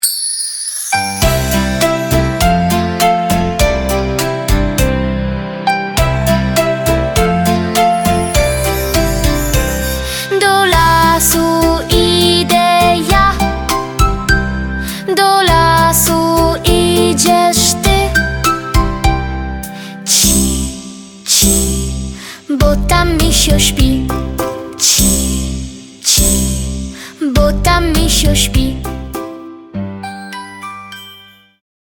prosta, rytmiczna piosenka dla dzieci